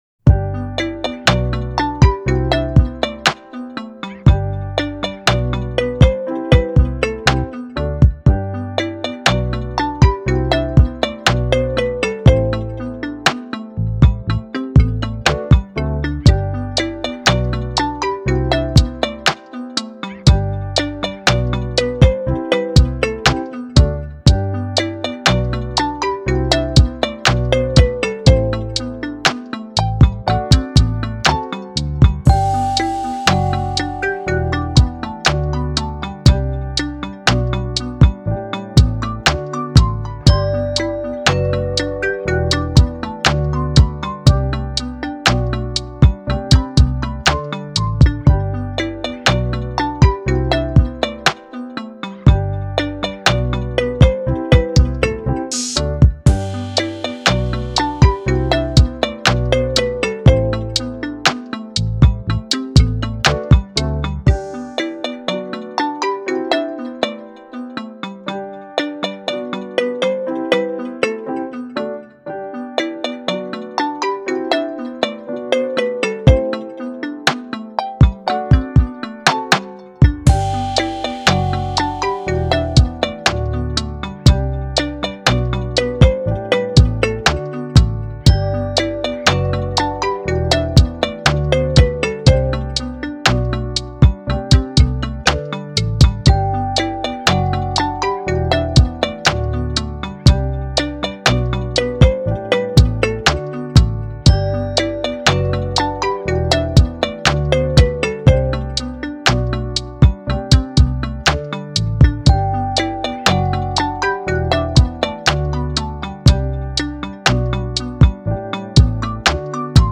チル・穏やか
明るい・ポップ